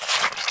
zombie_hit.ogg